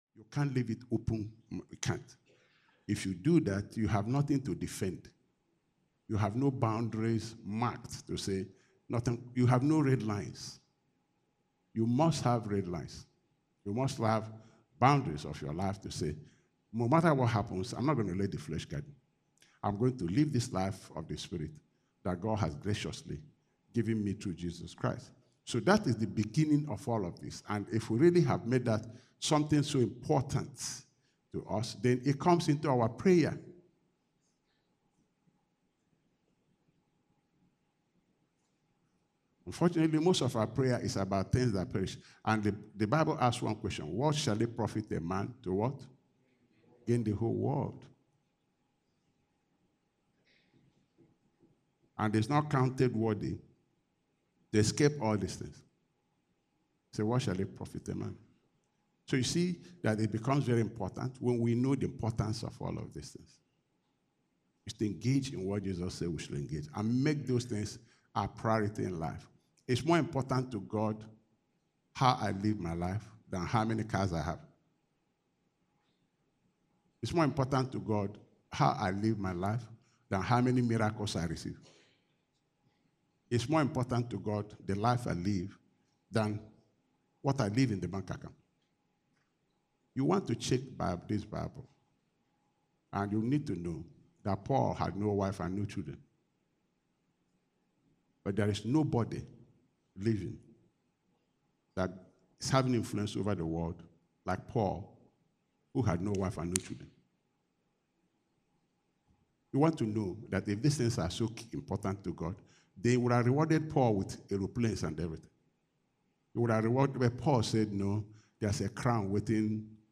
Bible Study